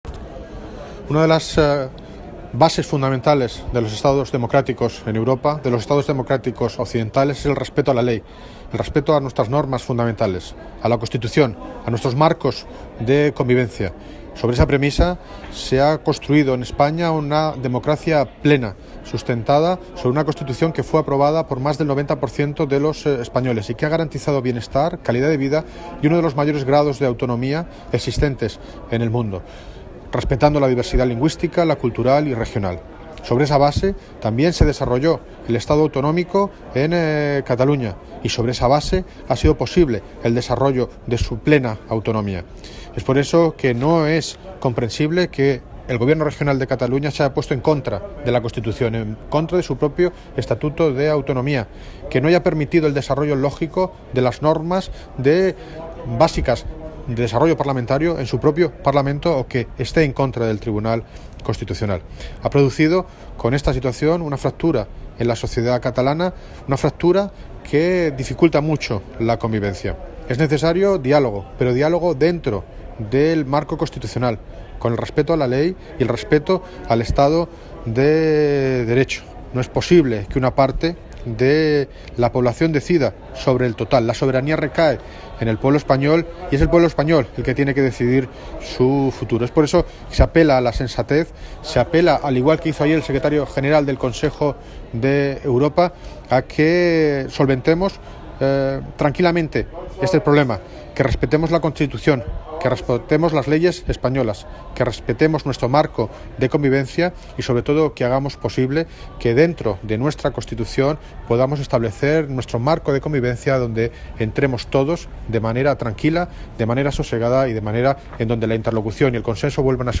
Noticia Fecha de publicación: 19 de octubre de 2017 Así se ha manifestado durante su intervención en el 33º Congreso del Consejo de Poderes Locales y Re...
Audio - David Lucas (Alcalde de Móstoles) Sobre INTERVENCION 33 CONGRESO